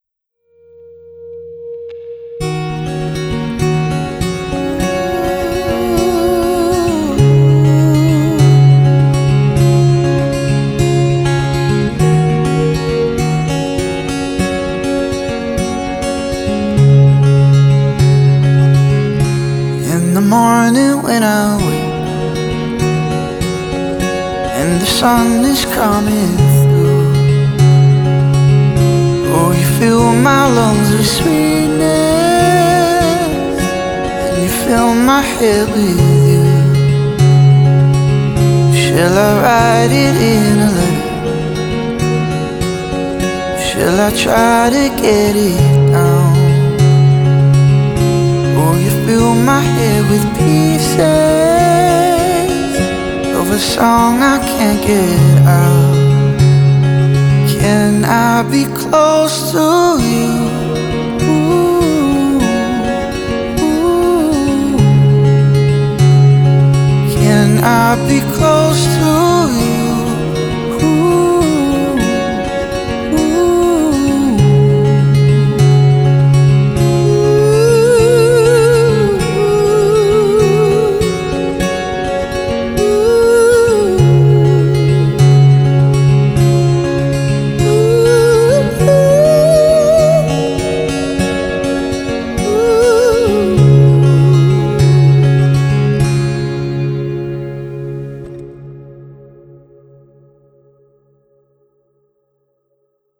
Vocal | Guitar | Looping